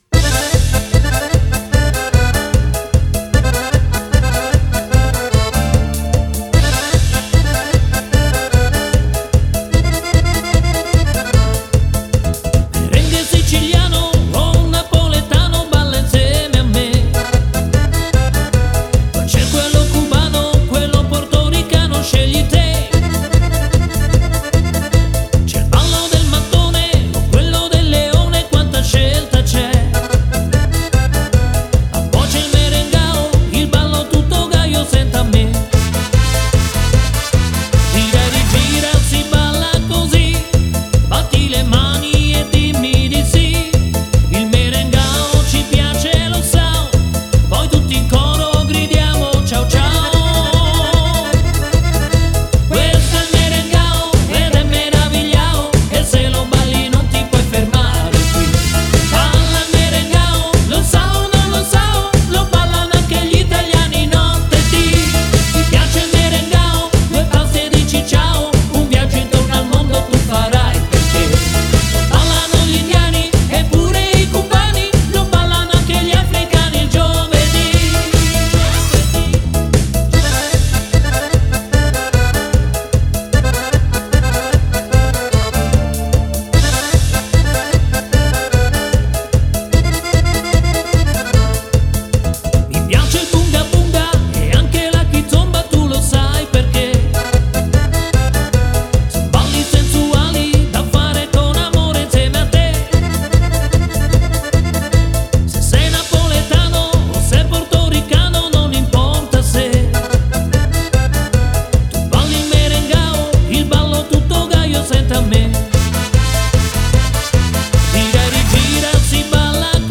Merengue